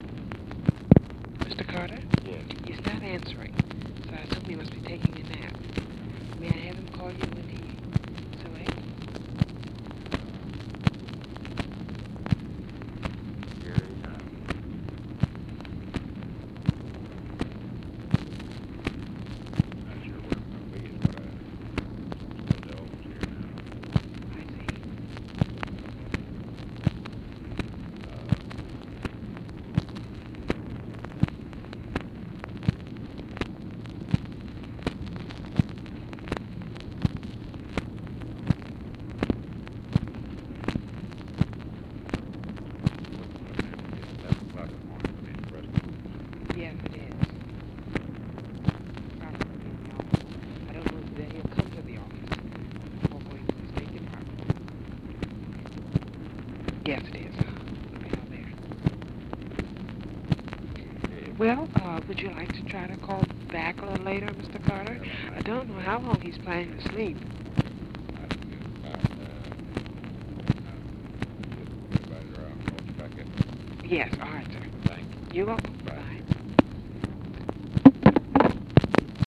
Conversation with OFFICE SECRETARY and (possibly) CLIFF CARTER, February 28, 1964
Secret White House Tapes